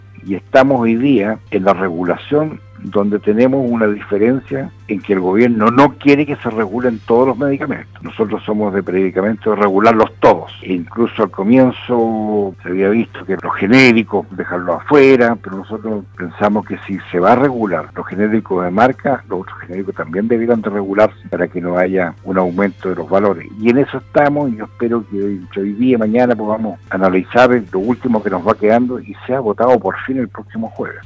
El senador socialista por la región de Los Lagos, Rabindranath Quinteros, conversó con Primera Hora de radio Sago sobre diversas iniciativas que pasaron y pasarán por la Comisión de Salud del Senado, la cual preside, durante estas semanas.